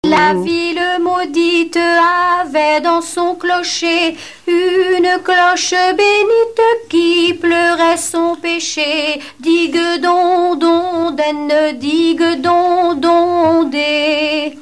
Contes et légendes chantés
Genre strophique